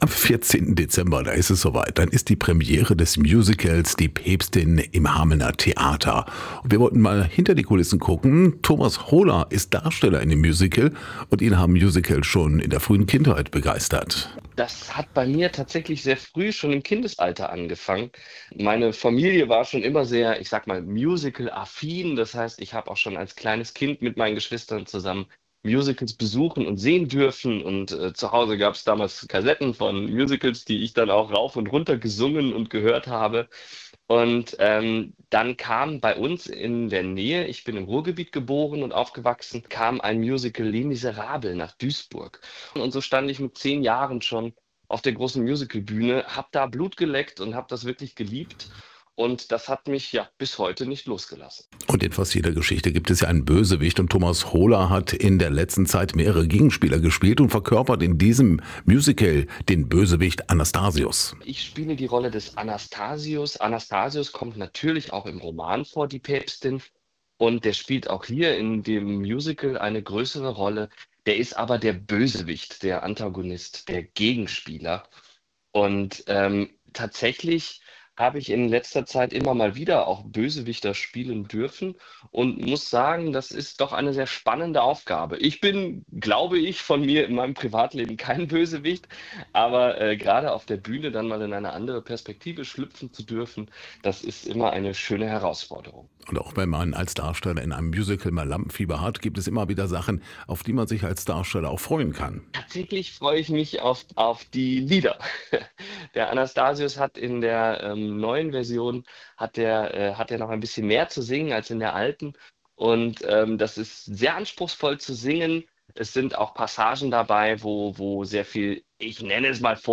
Aktuelle Lokalbeiträge